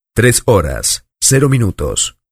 Grabación sonora de locutor retransmitiendo el siguiente comentario: "tres horas, cero minutos"
hombre
locutor
Sonidos: Voz humana